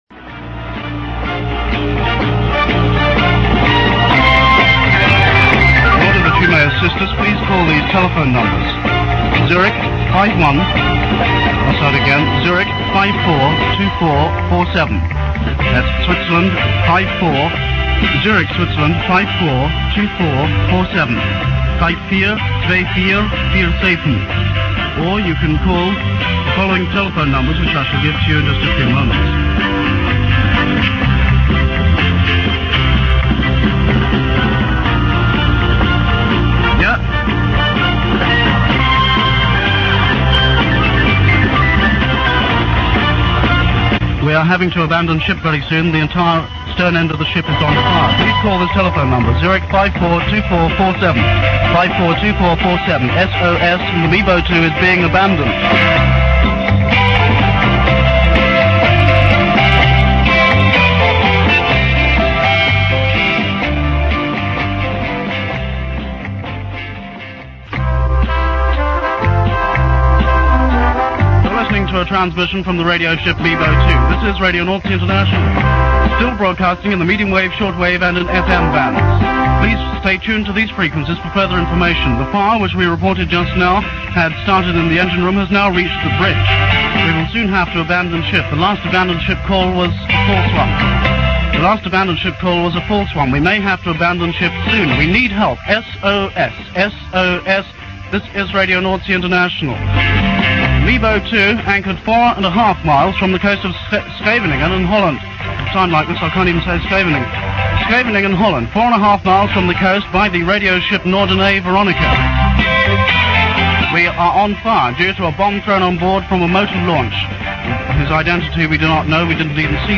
This and the previous clips are edited from a recording made available by The Offshore Radio Archive (duration 4 minutes 7 second)